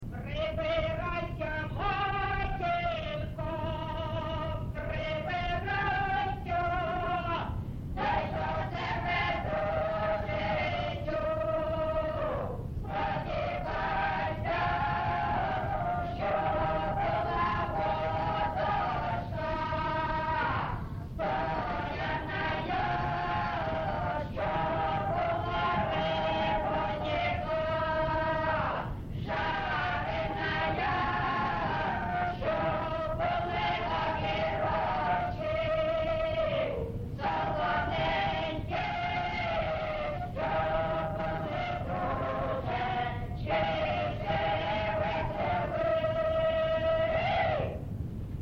ЖанрВесільні
Місце записус. Семенівка, Краматорський район, Донецька обл., Україна, Слобожанщина